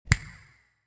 snap.wav